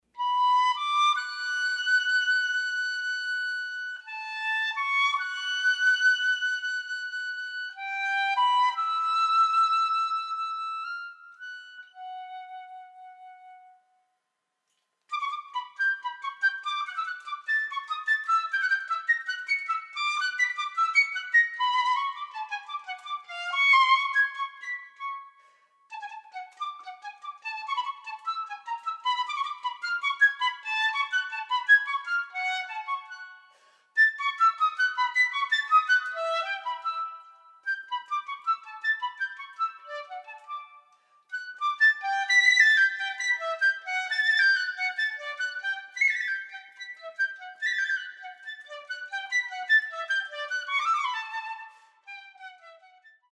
piccolo